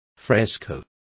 Also find out how frescos is pronounced correctly.